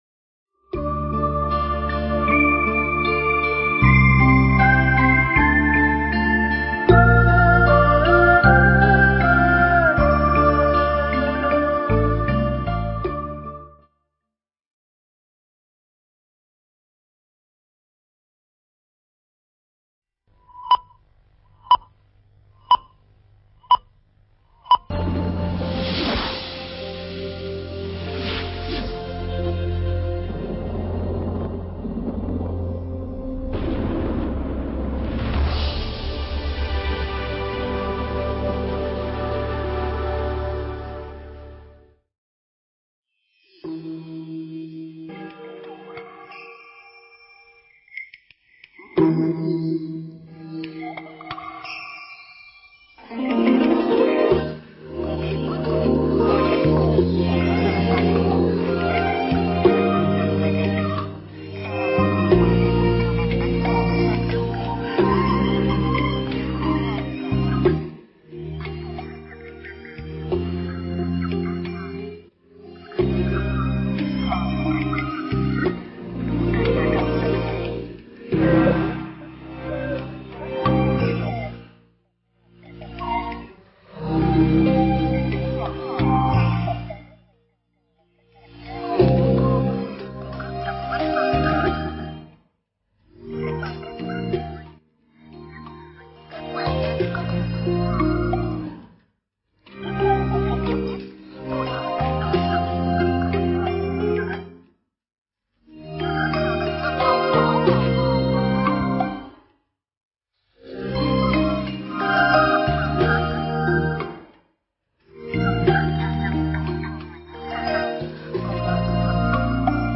Nghe Mp3 thuyết pháp 16 Điềm Chiêm Bao Của Vua Ba Tư Nặc
Mp3 Pháp âm 16 Điềm Chiêm Bao Của Vua Ba Tư Nặc